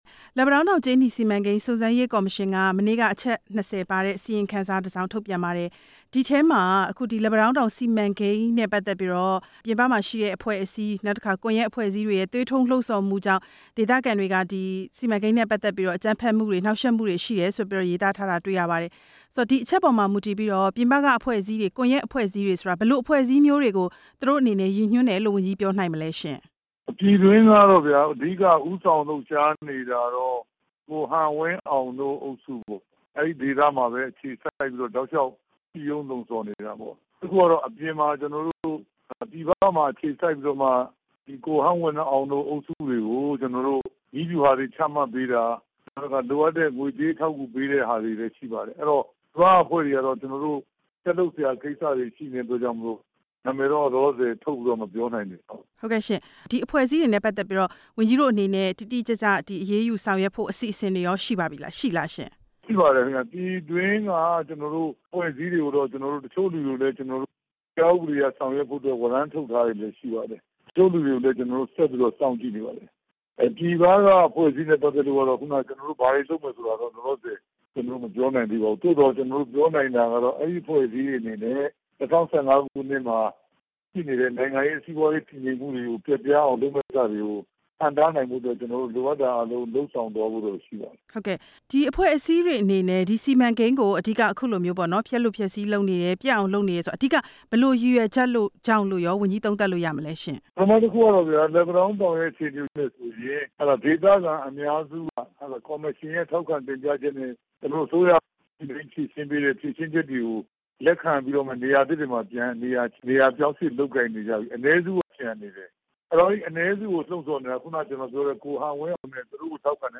ပြန်ကြားရေးဝန်ကြီး ဦးရဲထွဋ်ကို မေးမြန်းချက်